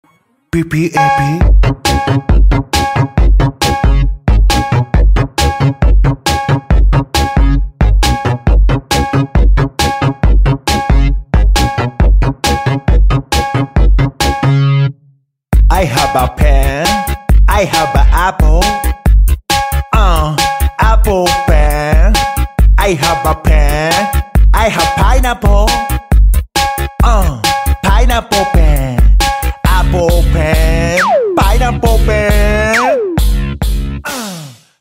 Kategória: Vicces
Minőség: 320 kbps 44.1 kHz Stereo